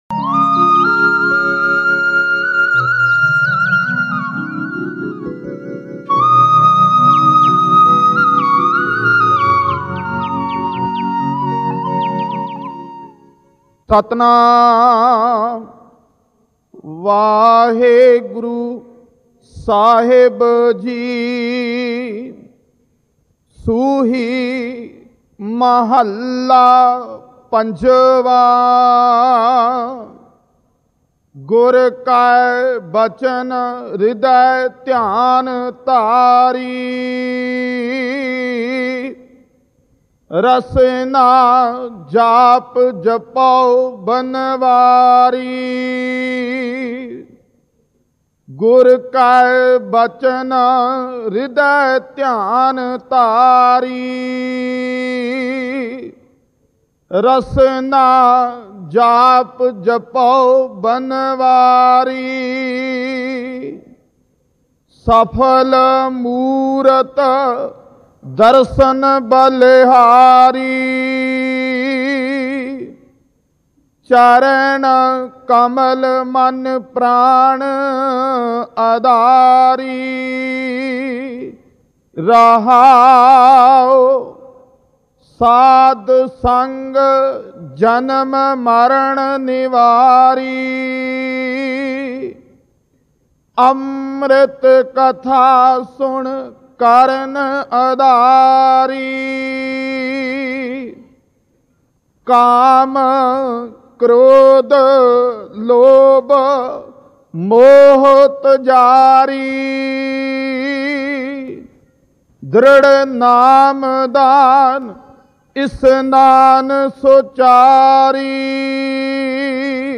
Katha
Gur Ke Bachan Ridai (Live Rec).mp3